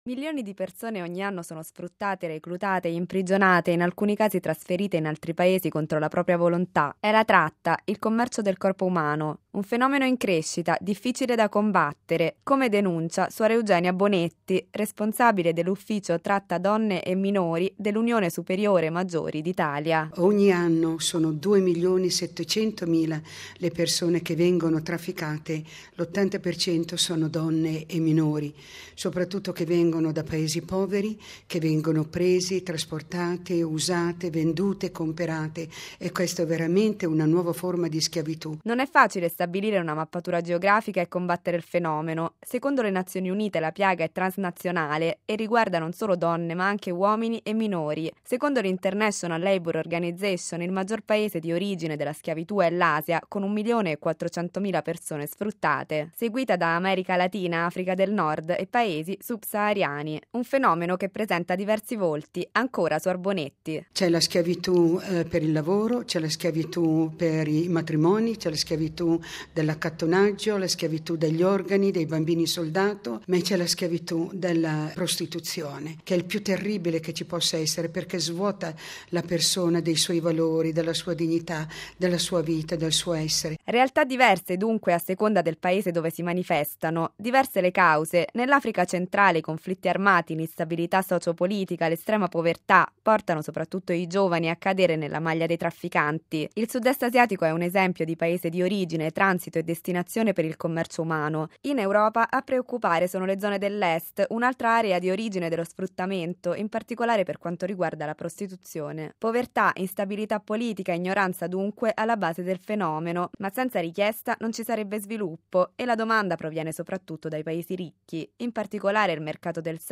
Per questo le delegate nazionali e regionali di "Talitha Kum", la Rete internazionale della Vita Consacrata contro il traffico di persone, sono riunite a Roma in una tre giorni di denunce, proposte e coordinamento delle attività future. Il servizio